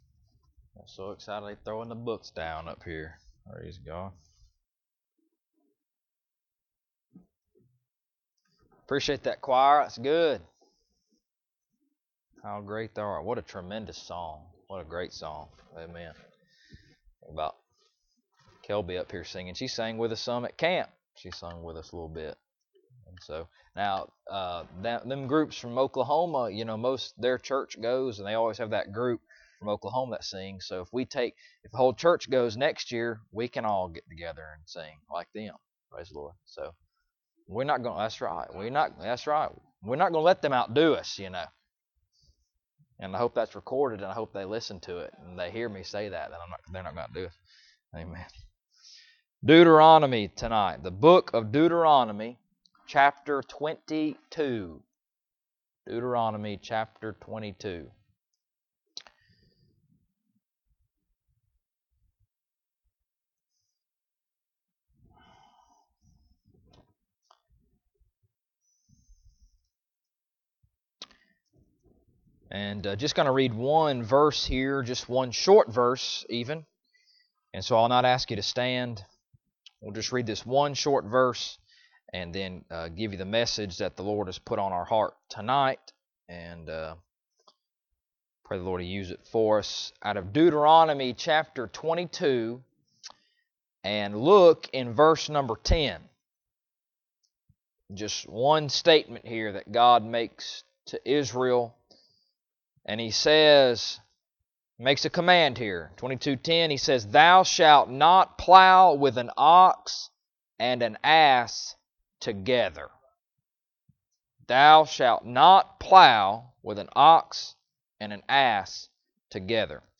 Deuteronomy 22:10 Service Type: Sunday Evening Bible Text